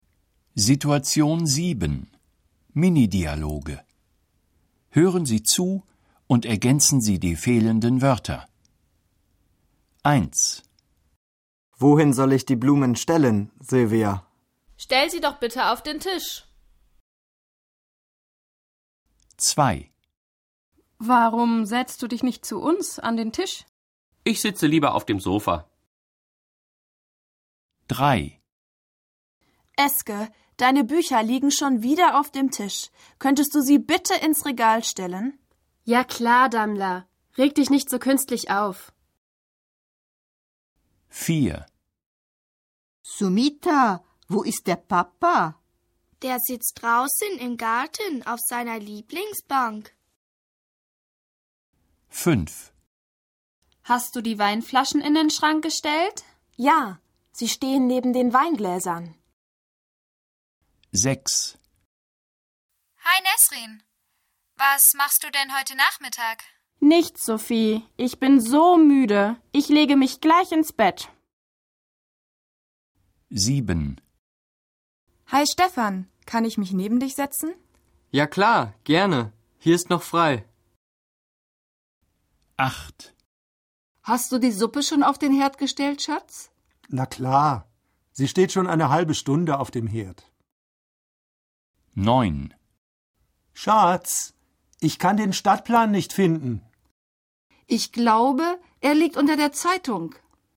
Situation 7 – Minidialoge (1609.0K)